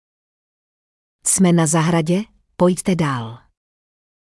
Text to speech zprávy v aplikaci
Převod textu na řeč